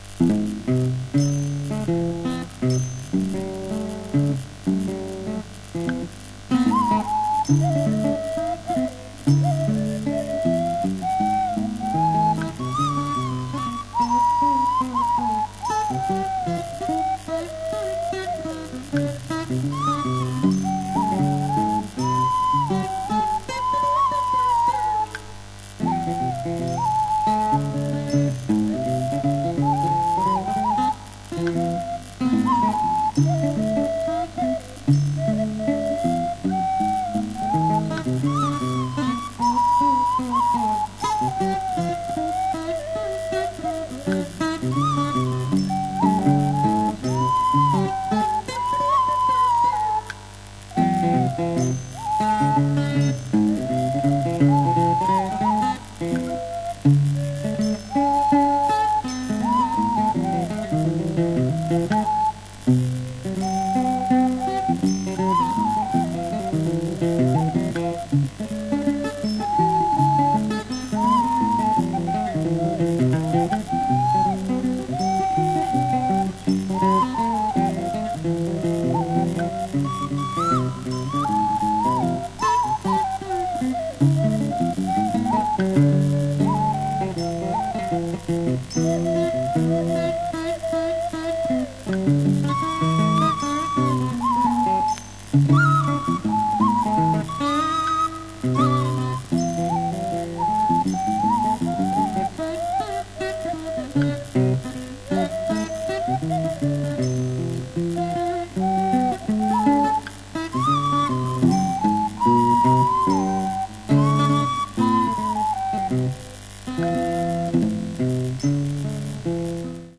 西洋からミャンマーへ沢山の弦楽器が流れてきた1800年代をモチーフにしたミャンマー音楽集！
※レコードの試聴はノイズが入ります。